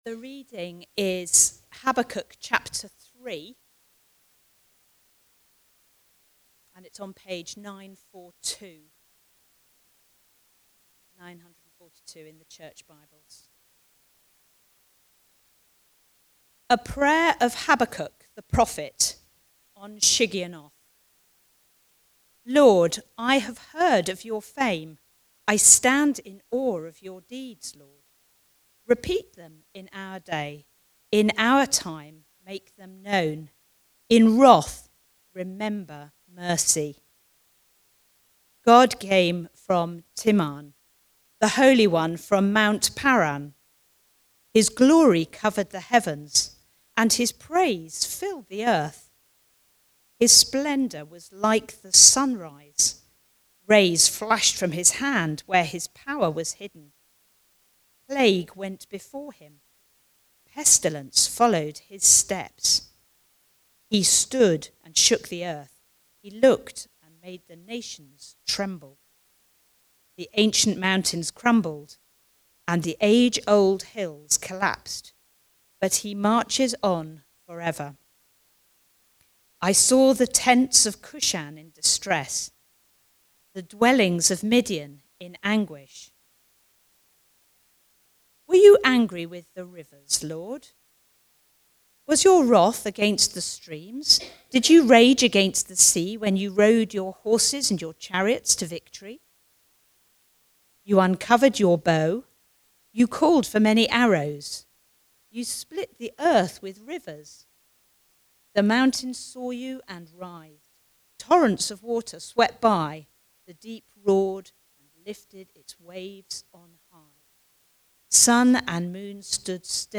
Preaching
Sing: I Will Rejoice (Habakkuk 3:1-19) from the series Learning to Live By Faith. Recorded at Woodstock Road Baptist Church on 18 May 2025.